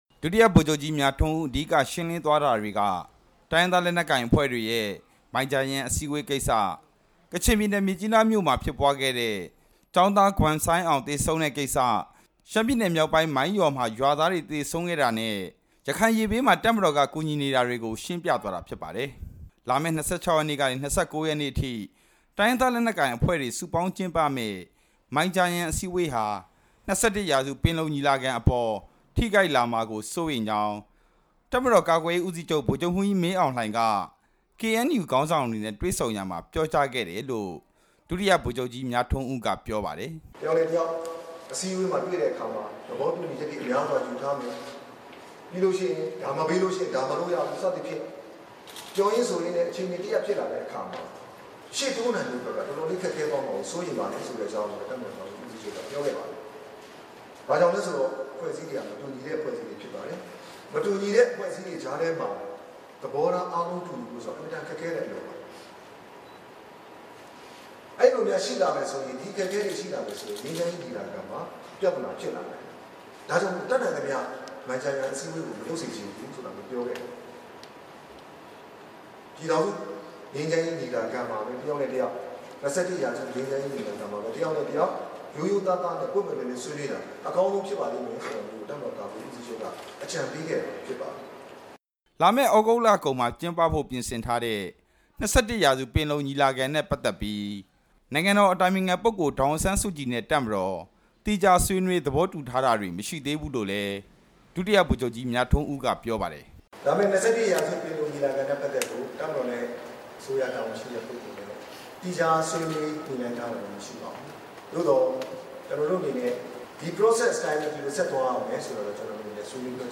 တပ်မတော် သတင်းစာရှင်းလင်းပွဲ